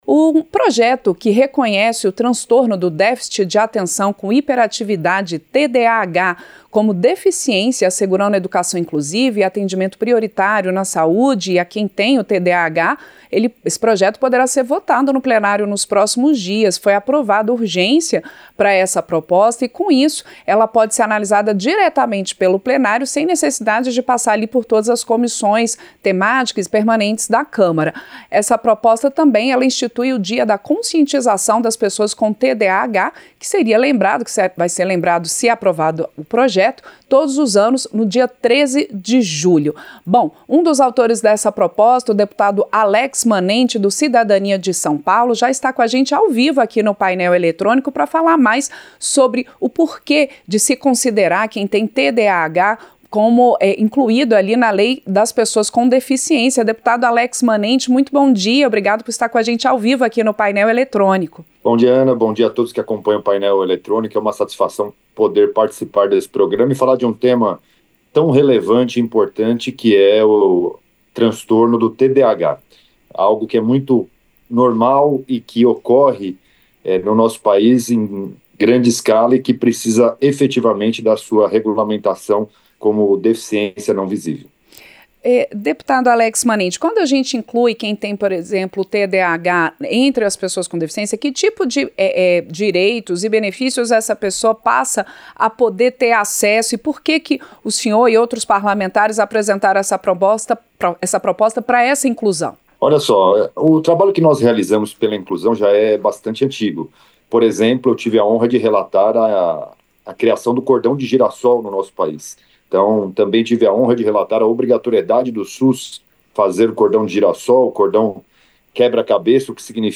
• Entrevista - Dep. Alex Manente (Cidadania-SP)
Programa ao vivo com reportagens, entrevistas sobre temas relacionados à Câmara dos Deputados, e o que vai ser destaque durante a semana.